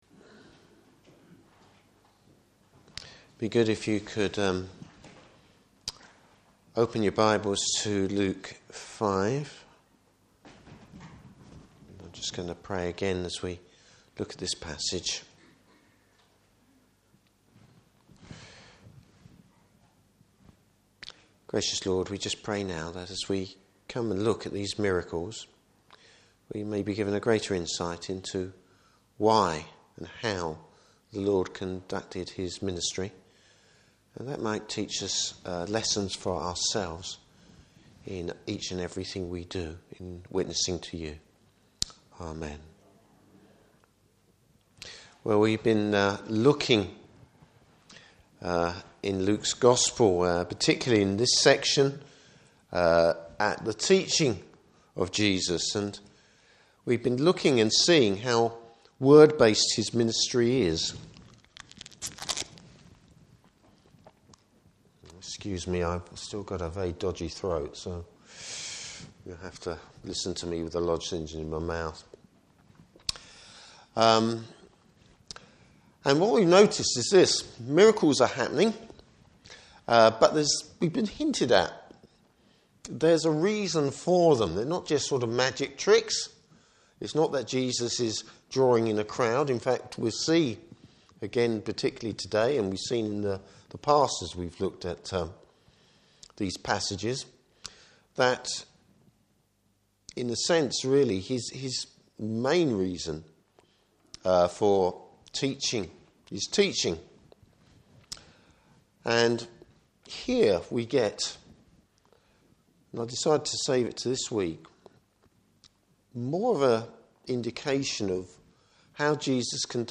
Service Type: Morning Service Bible Text: Luke 5:12-26.